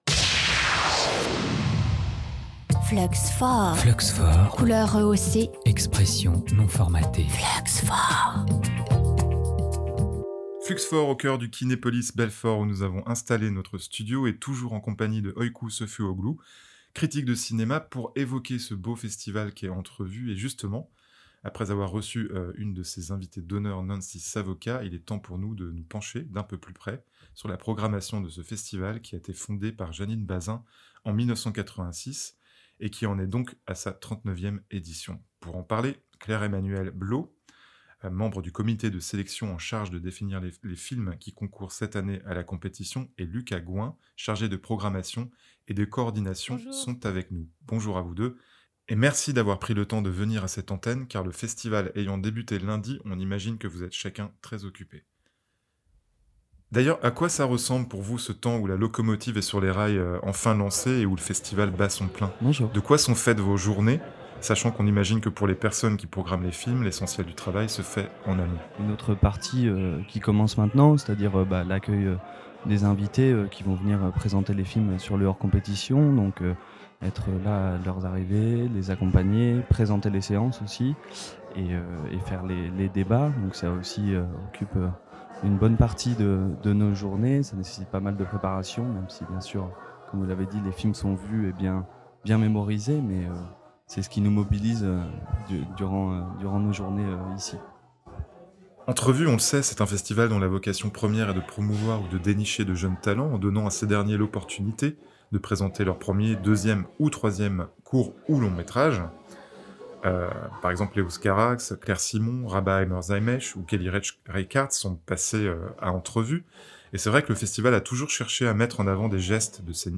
Rencontre avec le comité de sélection, Entrevues 2024